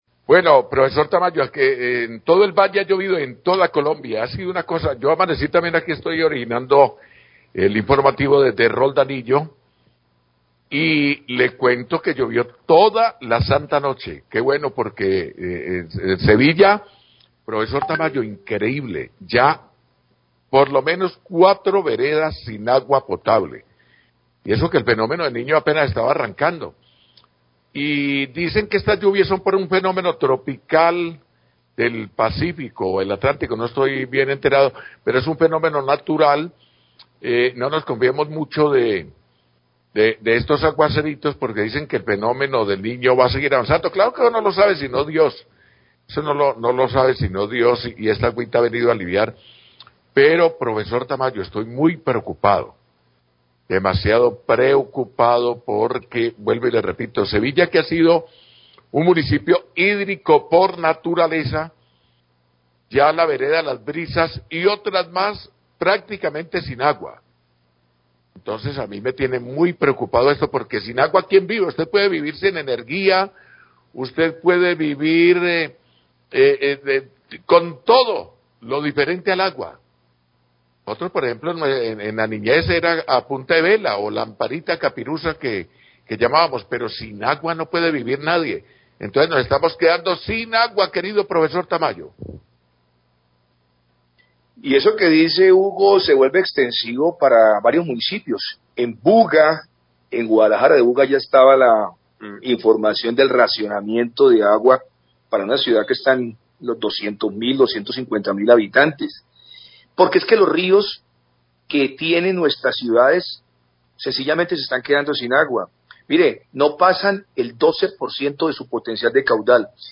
Periodista comenta sobre bajo nivel de agua en ríos del centro y norte del Valle
Radio